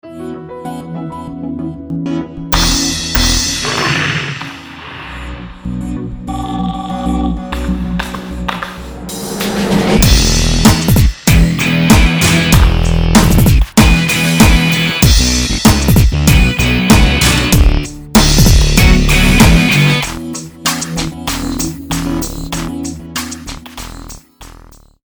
пример написания музыки и аудиомонтажа